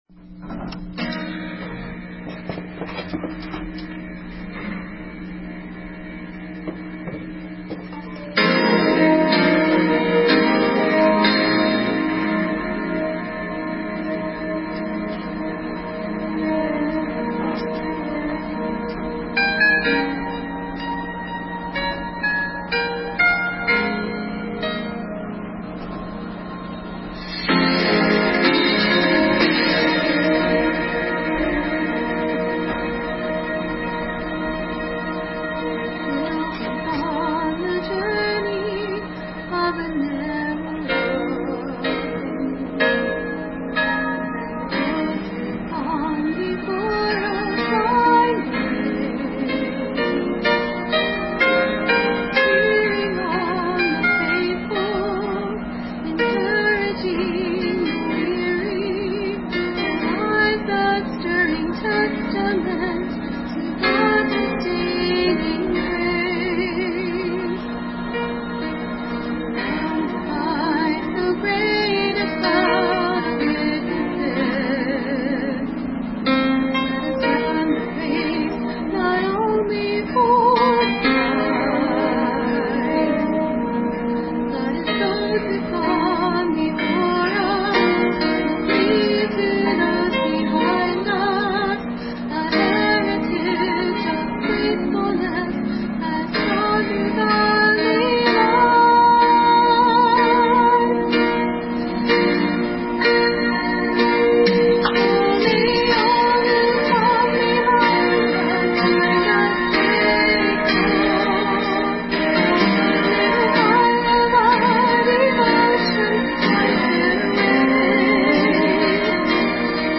February 17, 2013 Sermon Mark 9:14-29